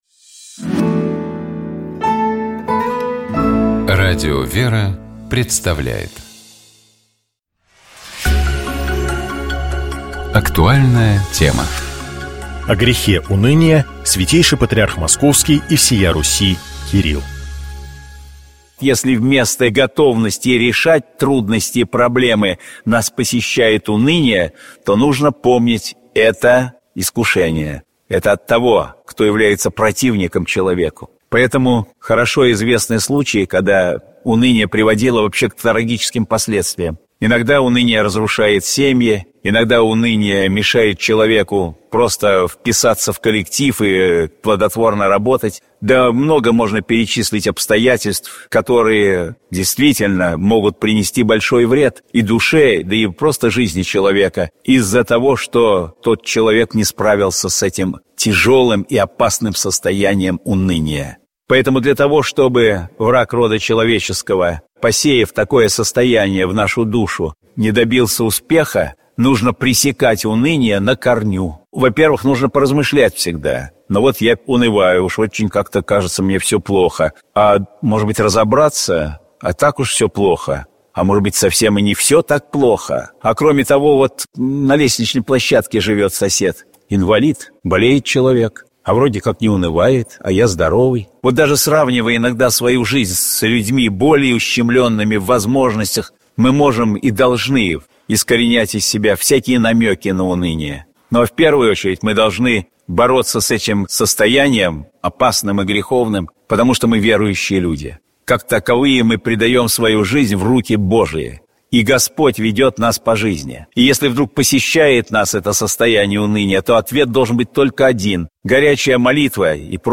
О грехе уныния — Святейший Патриарх Московский и всея Руси Кирилл.